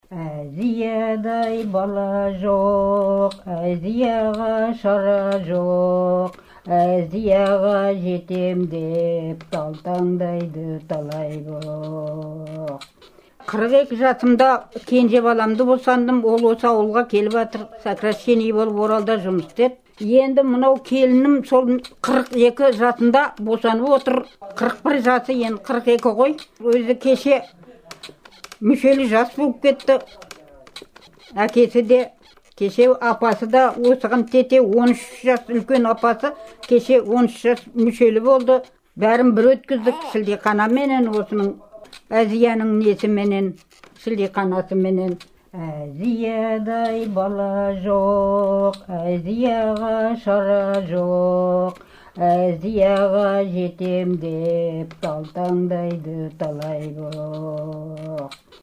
бесік жыры